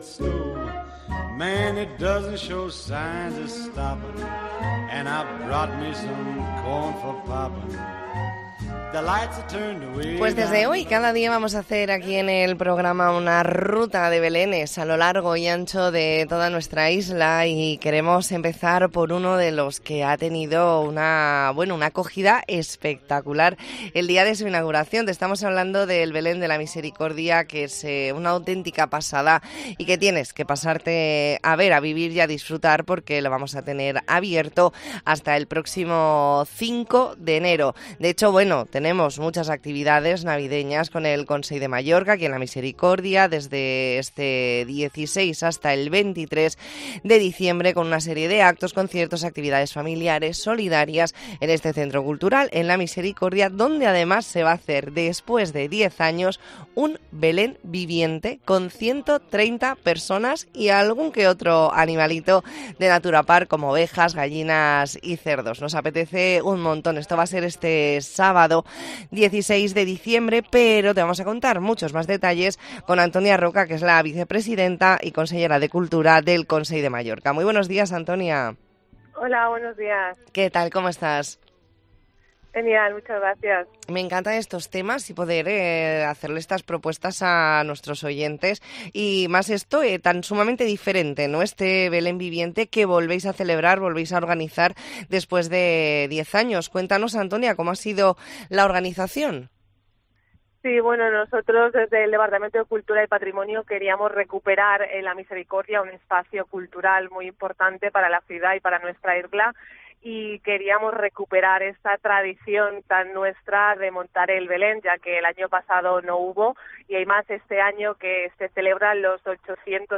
ntrevista en La Mañana en COPE Más Mallorca, martes 12 de diciembre de 2023.